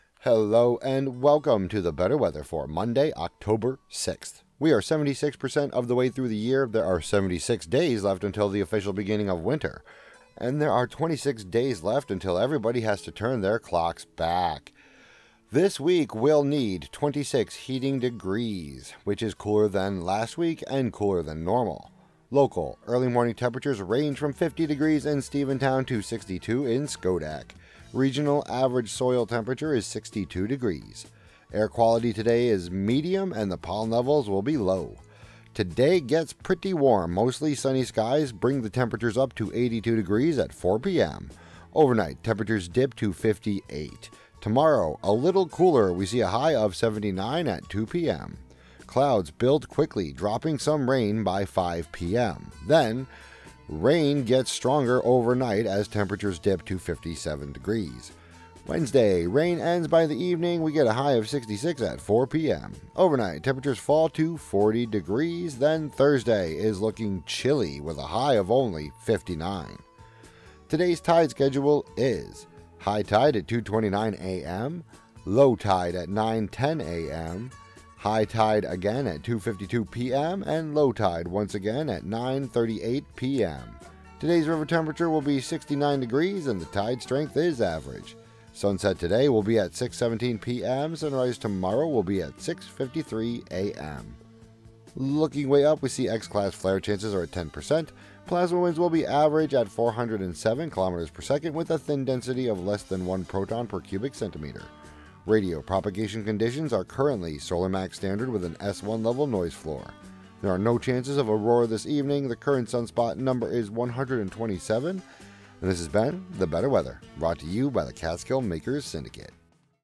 brings listeners meteorological predictions, pollen counts, Hudson River water temperatures, space weather, and more on WGXC 90.7-FM.